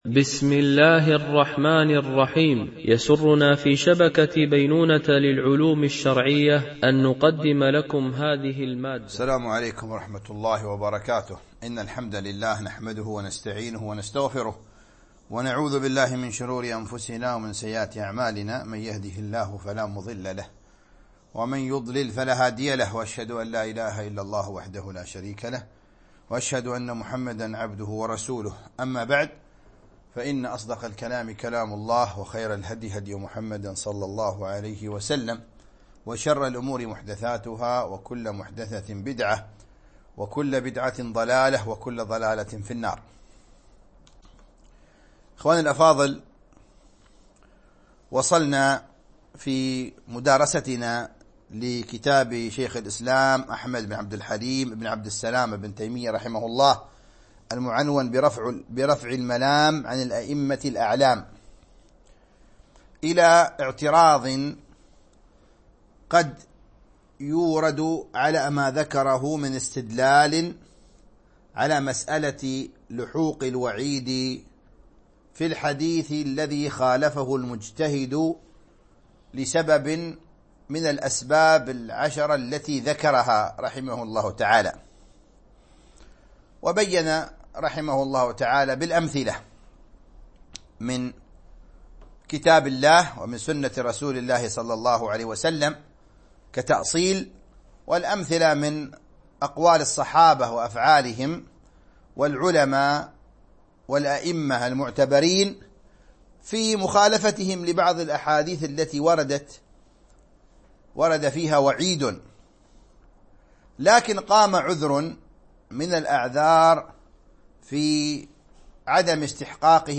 شرح كتاب رفع الملام عن الأئمة الأعلام ـ الدرس 15